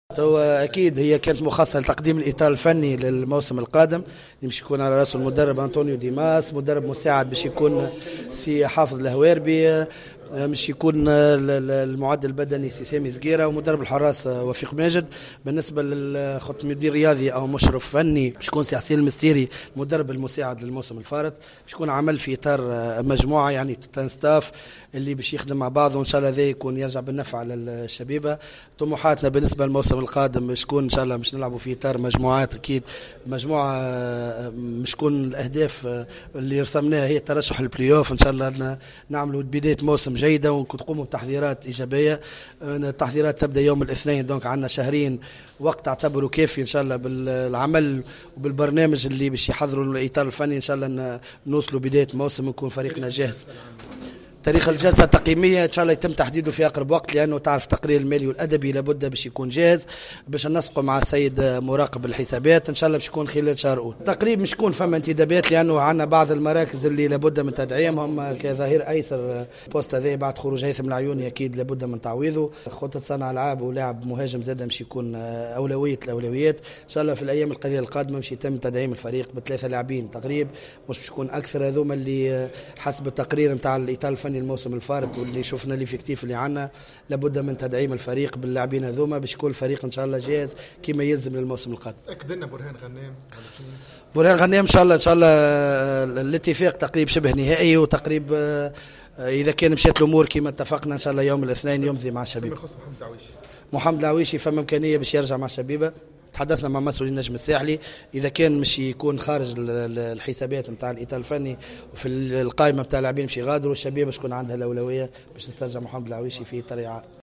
في تصريح خص به راديو جوهرة أف أم على هامش الندوة الصحفية المخصصة لتقديم الإطار الفني الجديد للفريق